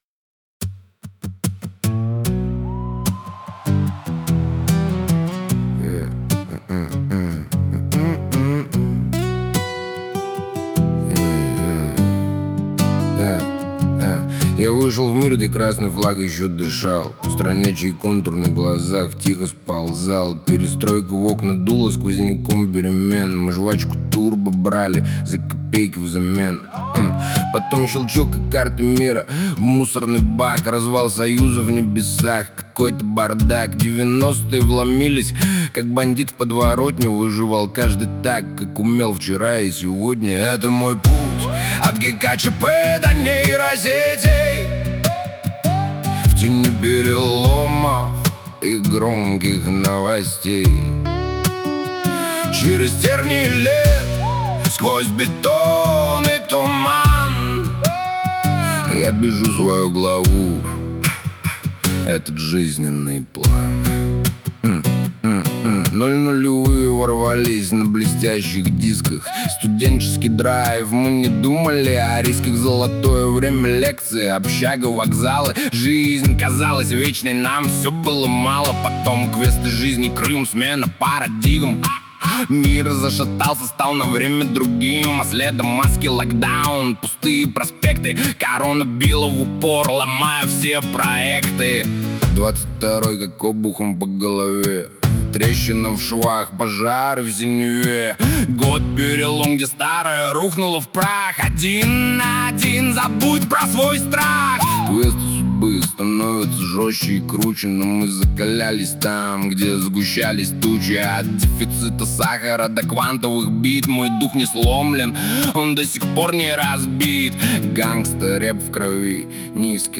Дворовые / РЭП под гитару